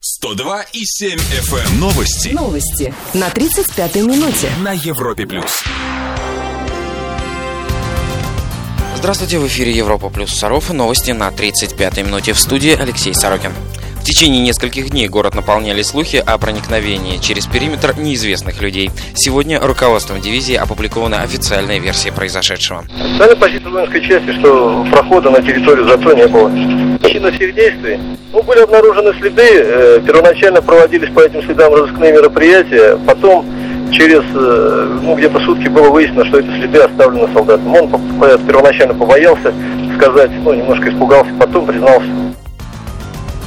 P. S. Отмазка армейского руководства в эфире «Европы+":
europap_plus_sarov_-_news_16.11.mp3